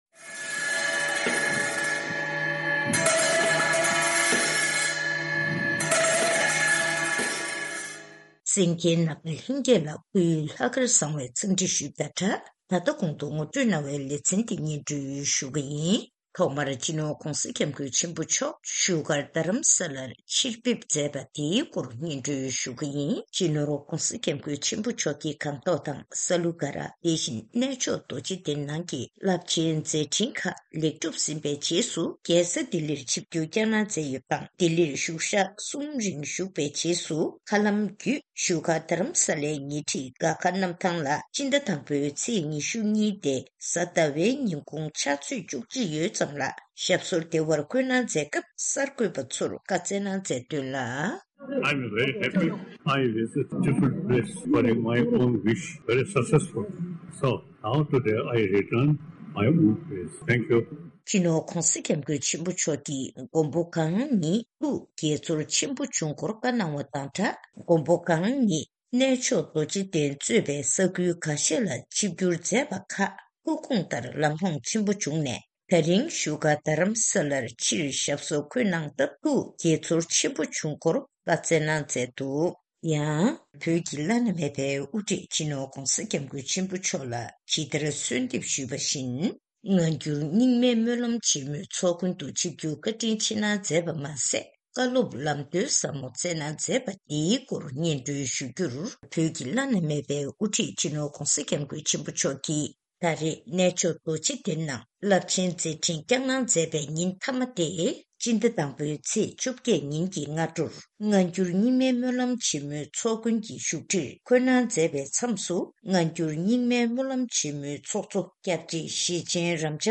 ཐེངས་འདིའི་སྔ་འགྱུར་རྙིང་མའི་སྨོན་ལམ་ཆེན་མོའི་སྐབས།